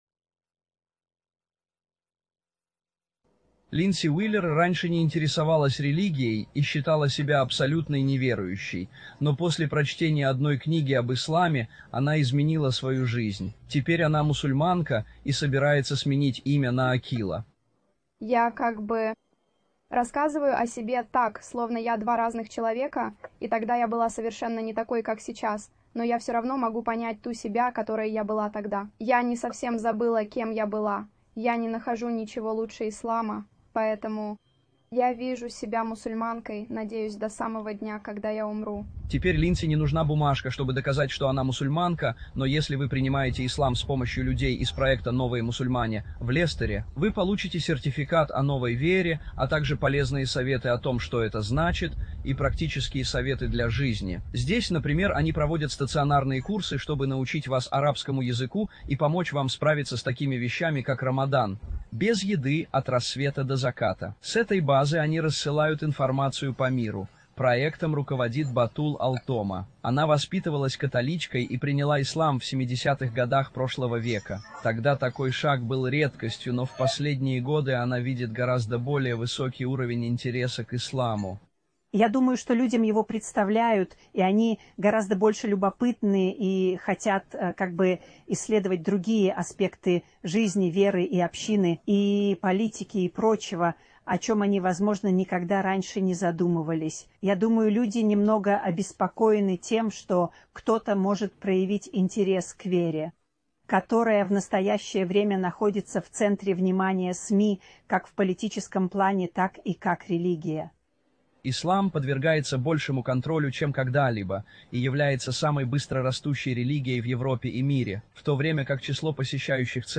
Описание: В этом выпуске новостей Five News репортёры рассказывают, как Ислам стал самой быстрорастущей религией в Великобритании и в Европе в целом.